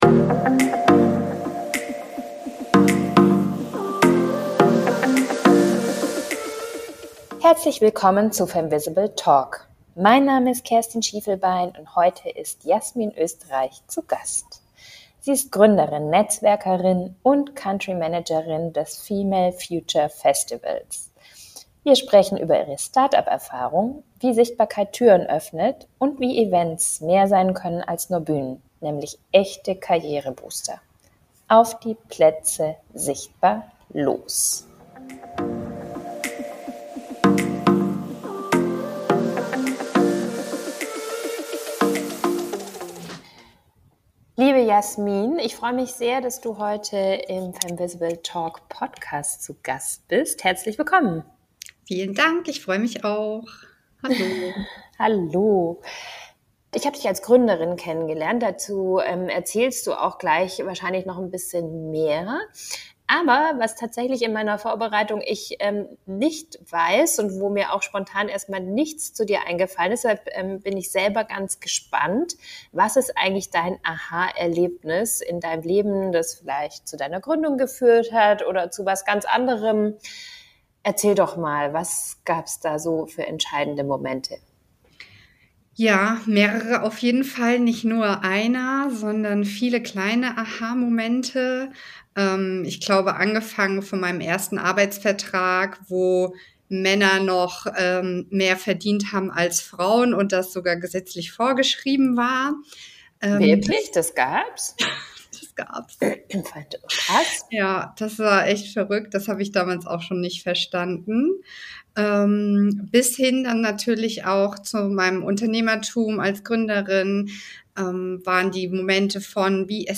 Ein Gespräch über Mut, Mindset und Märkte – und warum Female Empowerment kein Frauenthema ist.